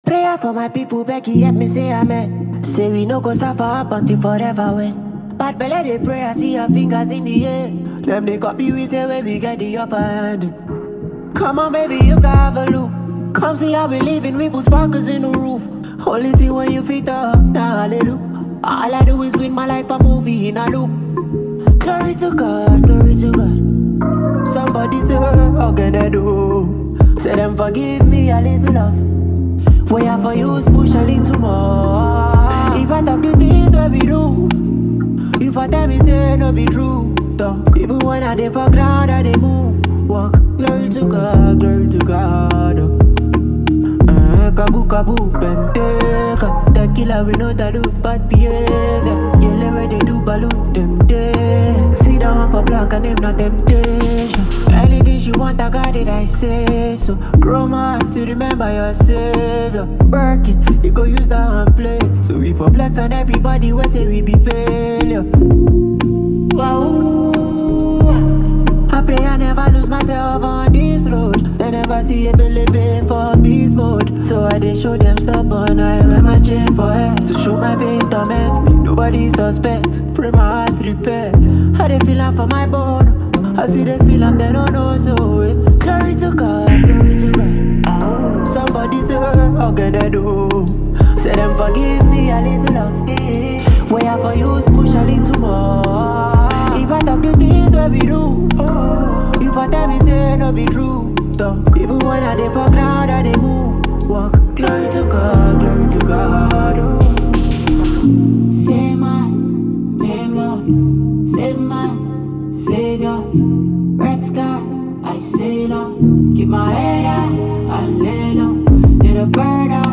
powerful and soul-lifting
rich Afrobeat rhythms
melodic vocals